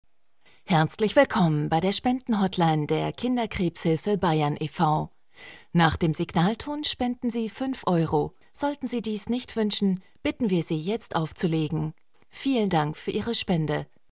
Finden Sie hier Hörbeispiele, Hörproben unserer Sprecher und Sprecherinnen und eine Auswahl an Audio-Demos.
Sprecher